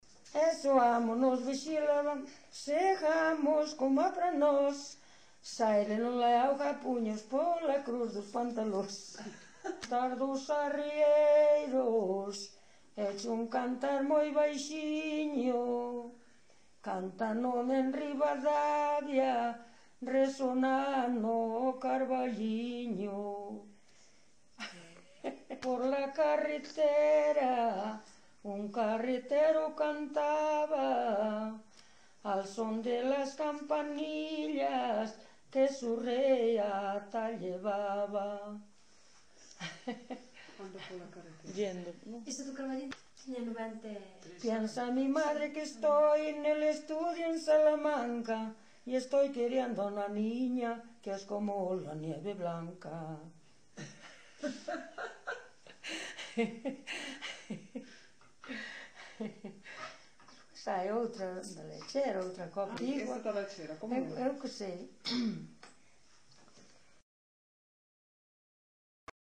Coplas.
Concello: Vila de Cruces.
Tipo de rexistro: Musical
Soporte orixinal: Casete
Datos musicais Refrán
Instrumentación: Voz
Instrumentos: Voz feminina